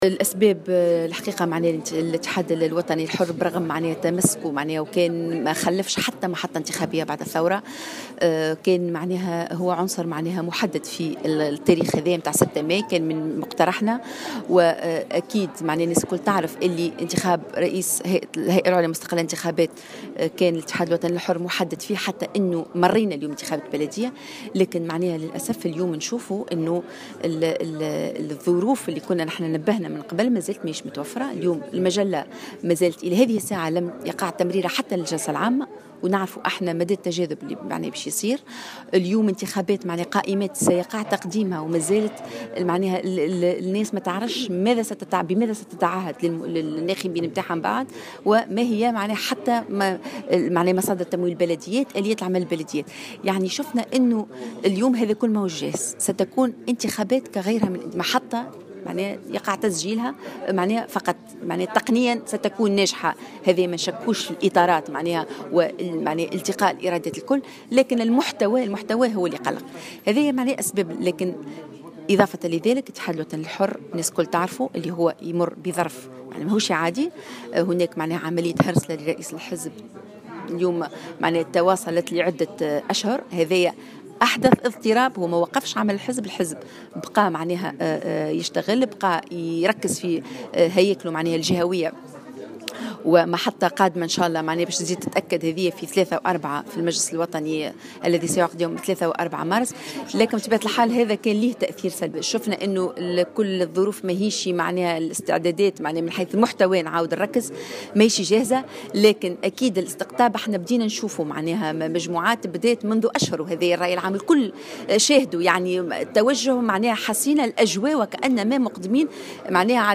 واعتبرت الشواشي في تصريح لمراسلة الجوهرة اف ام، أن الانتخابات البلدية ستكون محطة ناجحة تقنيا فقط، لكن محتواها مقلق، مشيرة إلى أن الحزب سيعقد مجلسه الوطني في شهر مارس القادم للنظر في مسألة استقالة الرئيس سليم الرياحي.